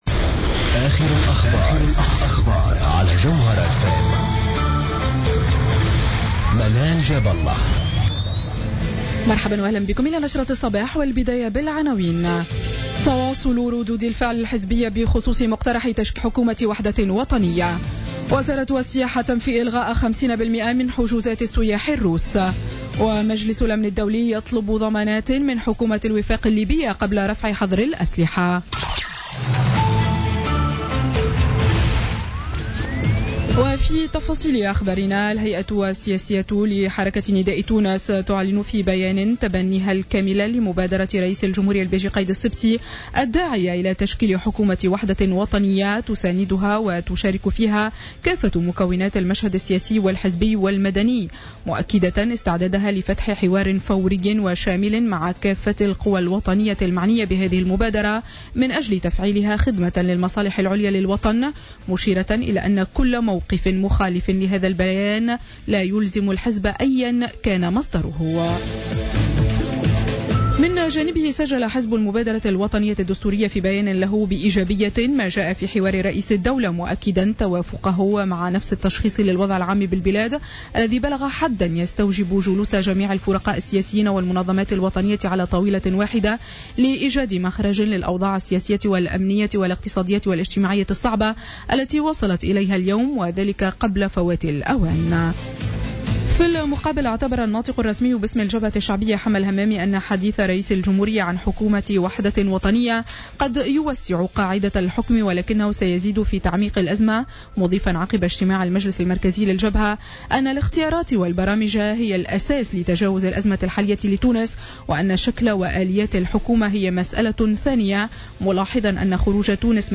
نشرة أخبار السابعة صباحا ليوم السبت 4 جوان 2016